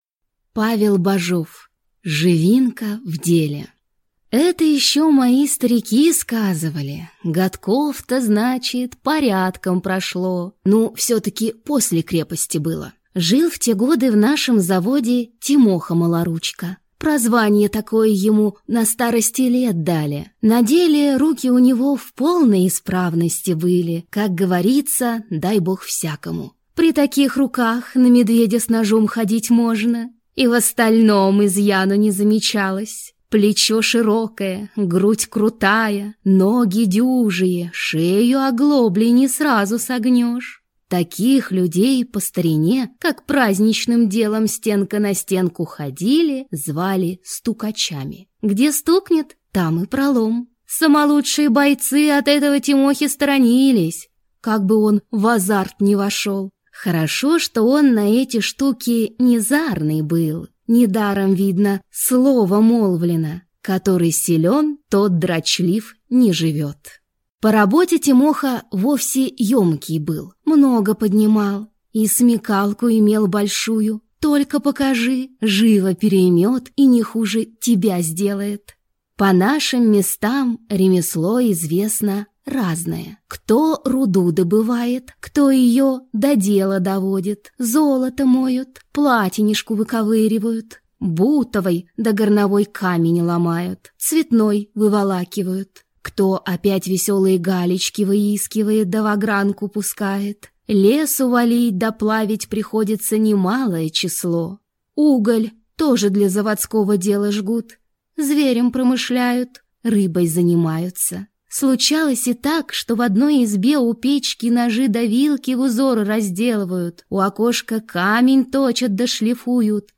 Аудиокнига Живинка в деле | Библиотека аудиокниг